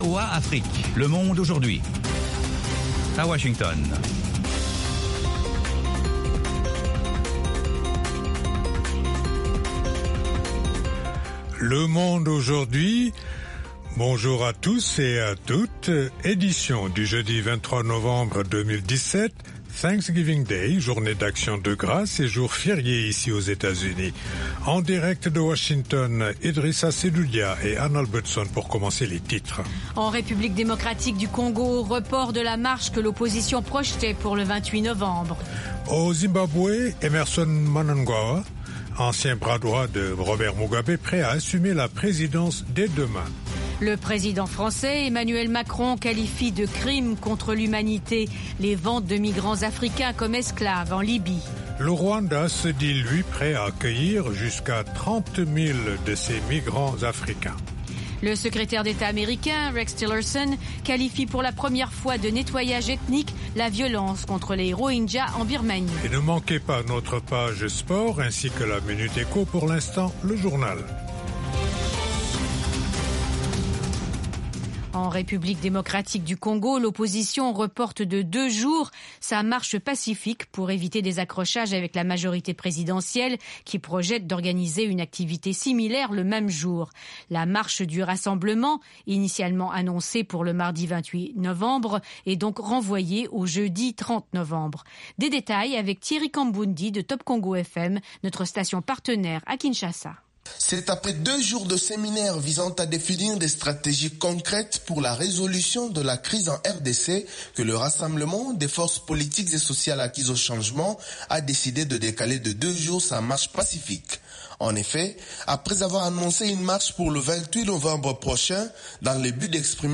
Toute l’actualité sous-régionale sous la forme de reportages et d’interviews. Des dossiers sur l'Afrique etle reste du monde. Le Monde aujourd'hui, édition pour l'Afrique de l’Ouest, c'est aussi la parole aux auditeurs pour commenter à chaud les sujets qui leur tiennent à coeur.